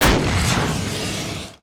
engine_start_004.wav